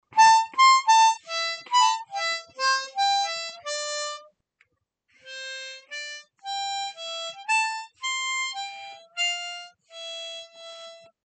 even if you don’t include the notes that need bending, it sounds “wrong”, any ideas?
any ideas why it sounds weird?
C Diatonic